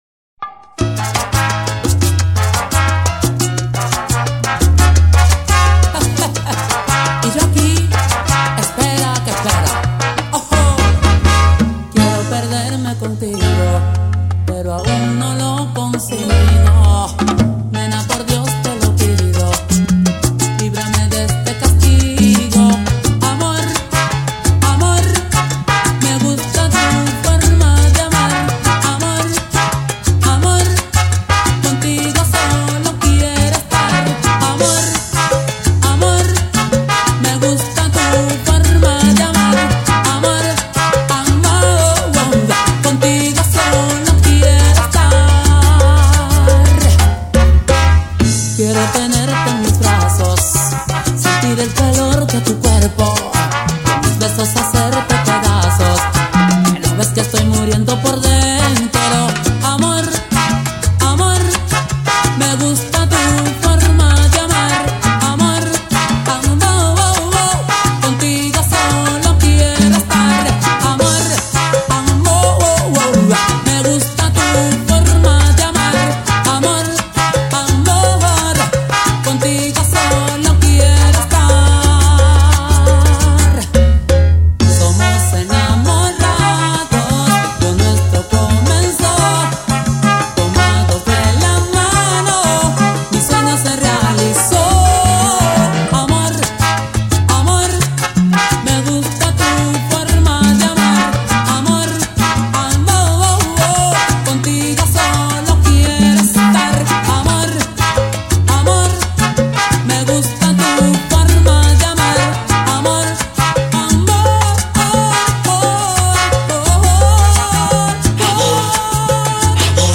Disfruta de este género musical, Como es la salsa!